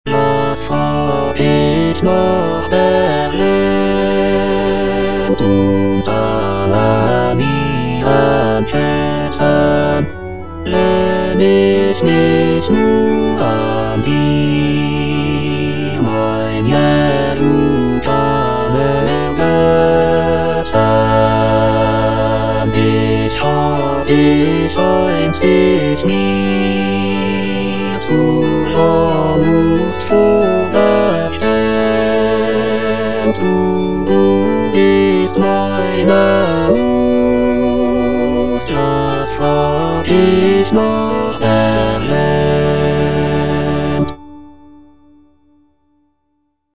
J.S. BACH - CANTATA "SEHET, WELCH EINE LIEBE" BWV64 Was frag' ich nach der Welt - Bass (Emphasised voice and other voices) Ads stop: auto-stop
This cantata was written for the third day of Christmas and was first performed in 1723. It consists of seven movements, including a sinfonia, recitatives, arias, and a chorale.